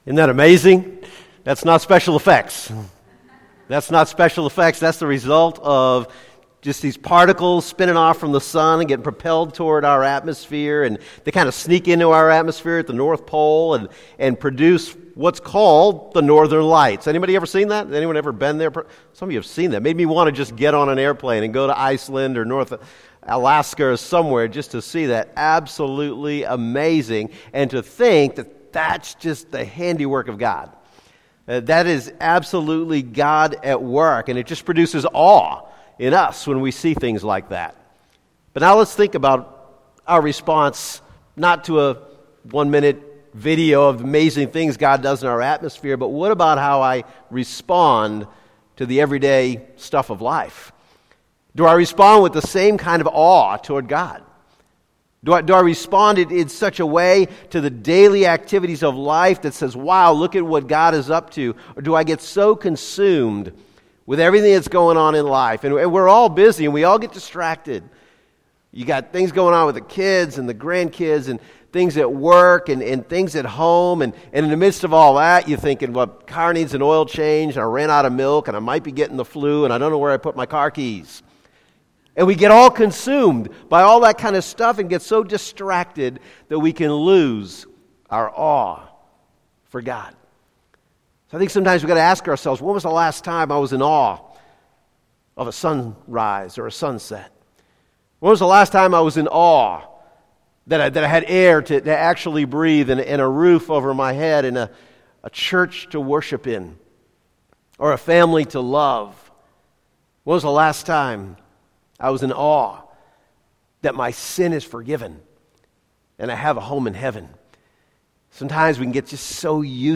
Sermons - Calvary Baptist Bel Air